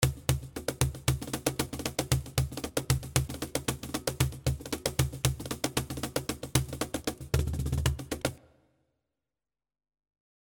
115 Bpm Cajon loops (7 variations)
The cajon loops are at 115 bpm playing baladi style.
The 7 loops you can download are with reverb, 1.5 seconds long.